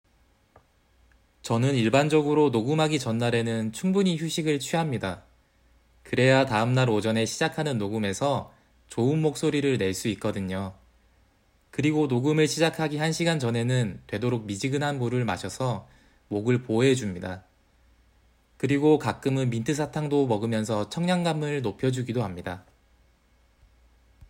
(He also recorded his responses at a normal speaking speed and tone.)